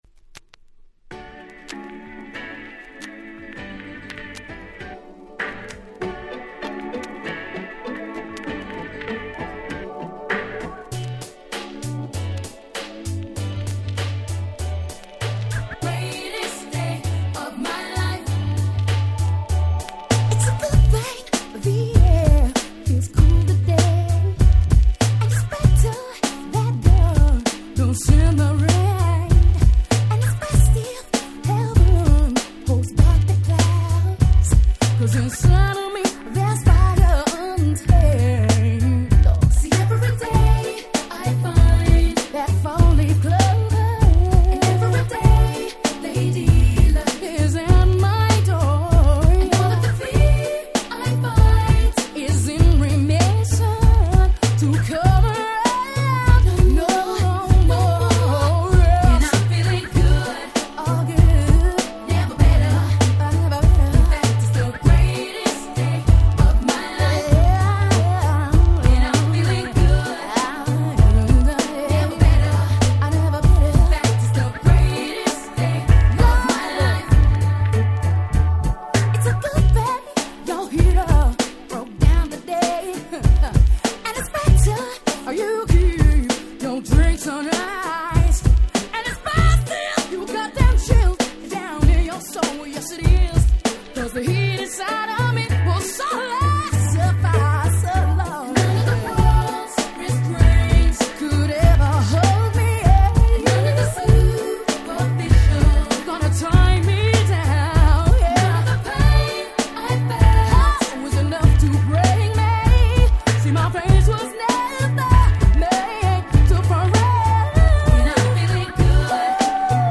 99' Nice UK R&B !!